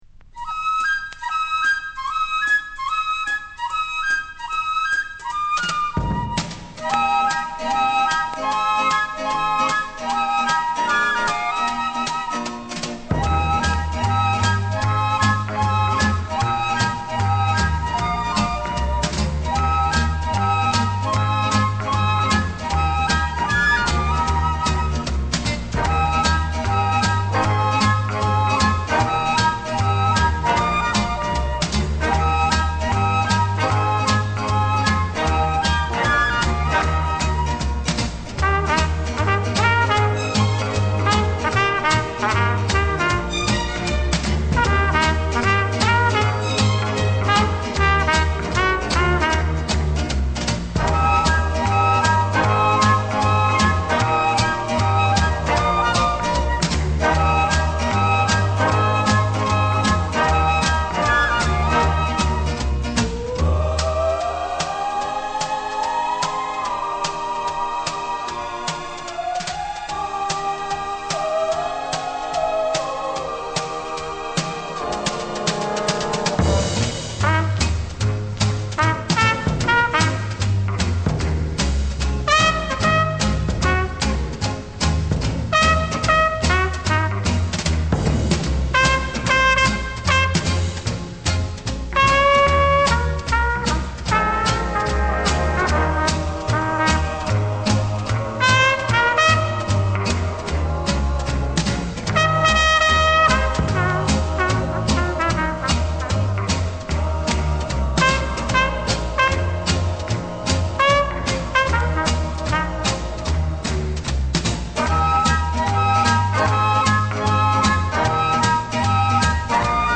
Composer-conductor-arranger